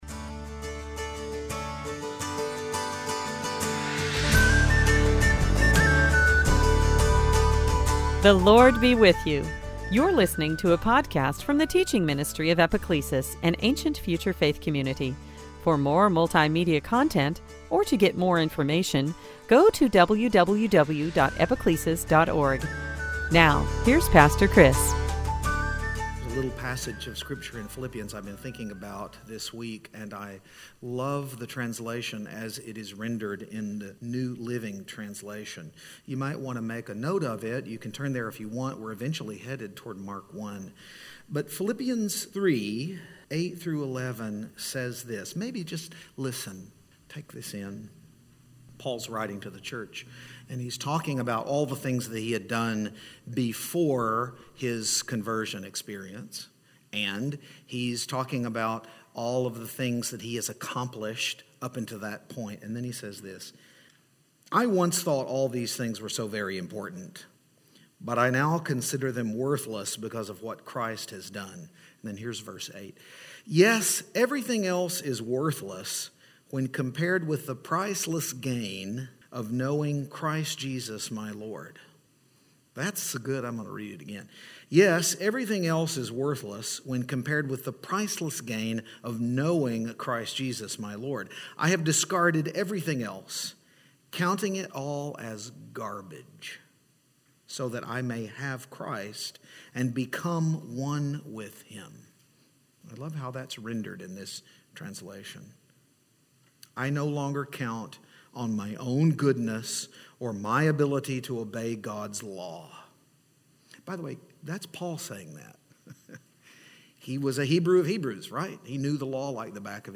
2021 Sunday Teaching Adam Children of Israel desert Lent redemption rescue Satan temptation testing trial Lent